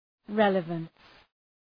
{‘reləvəns}